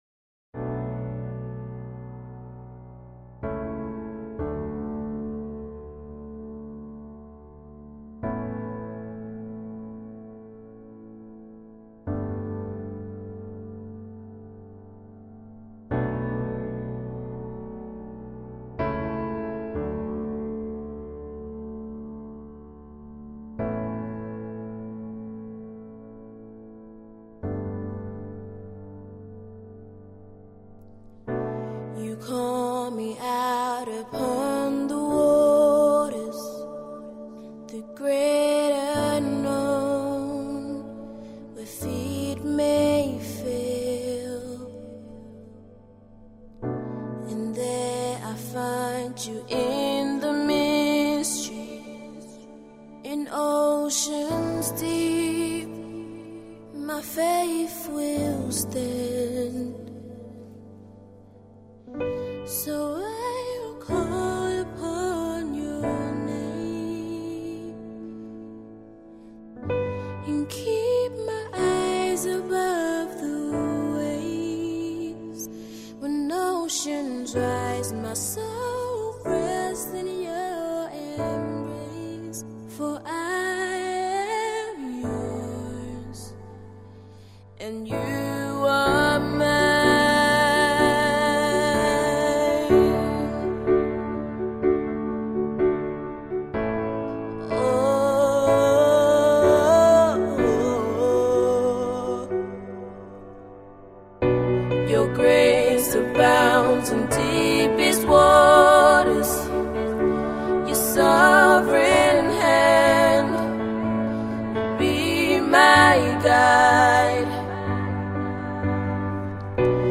Gospel 2024